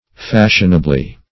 fashionably - definition of fashionably - synonyms, pronunciation, spelling from Free Dictionary
Fashionably \Fash"ion*a*bly\, adv.